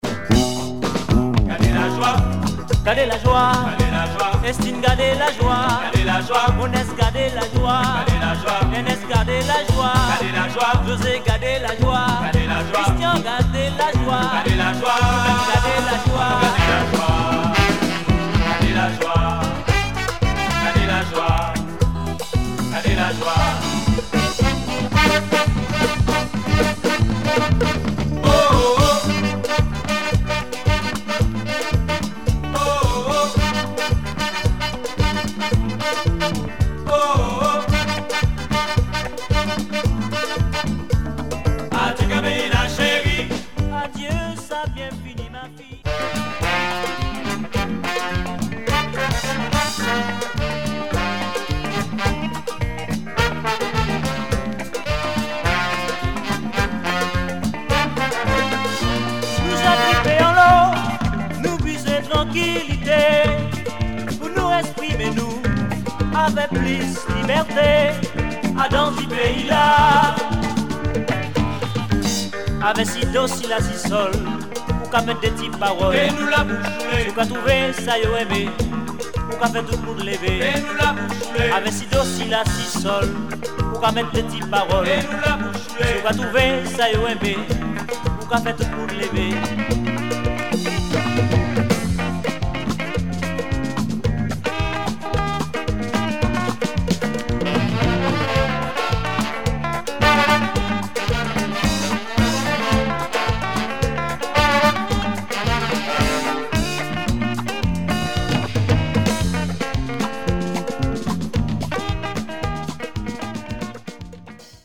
Caribbean sounds !